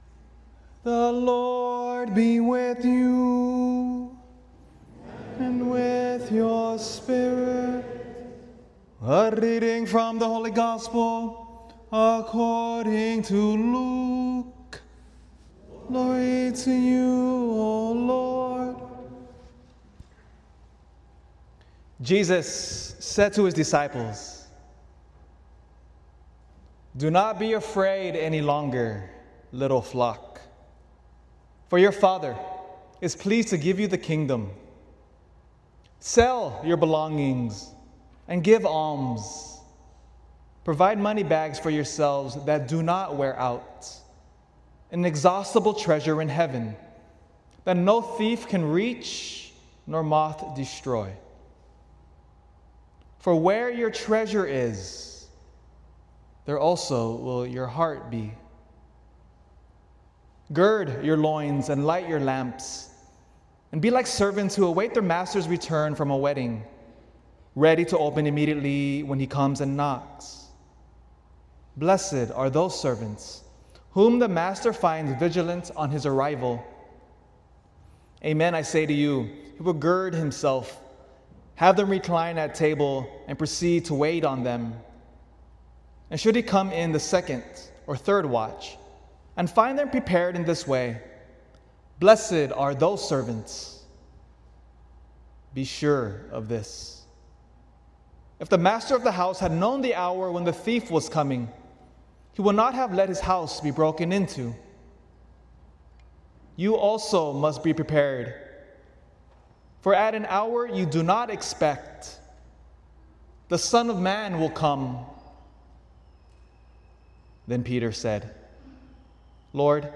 ***Bonus hymn at the end of the homily from the 10AM Mass***